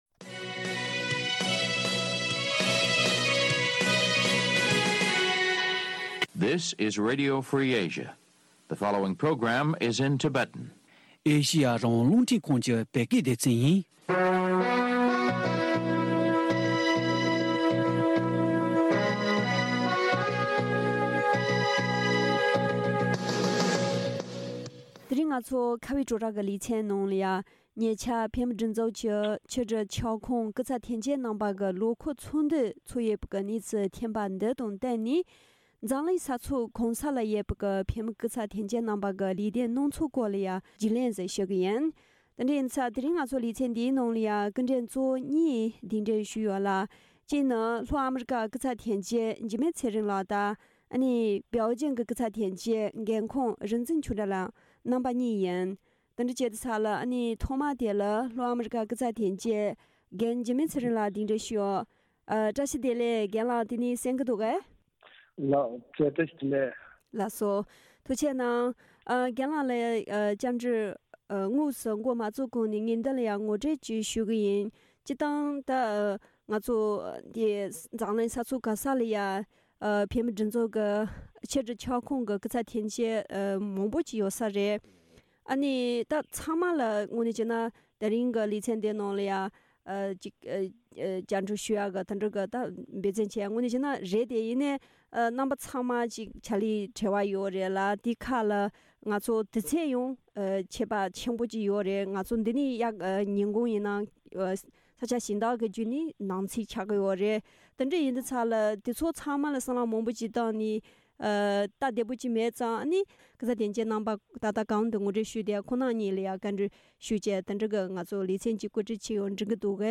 བཀའ་འདྲི་ཞུས་པར་གསན་རོགས་ཞུ།